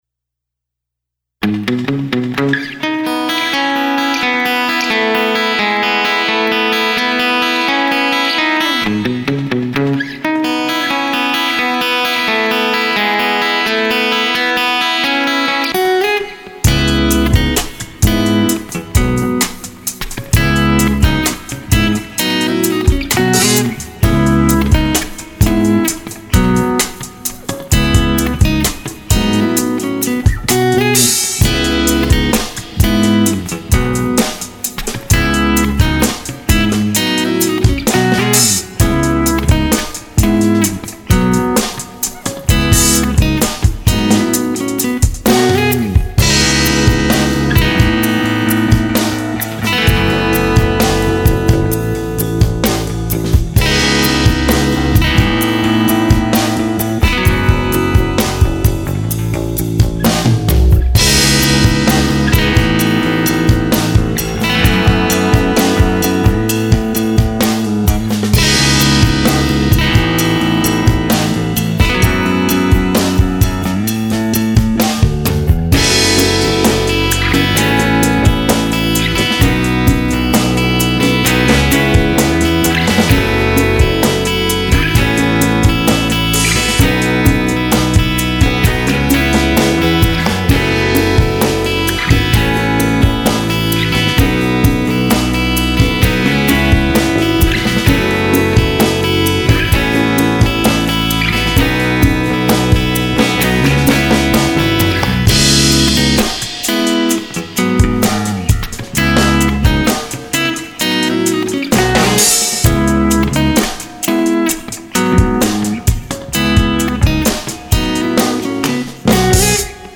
カジュアル・ロック系のフリーBGM
シンプル構成でスローテンポ、明るめな曲になっています。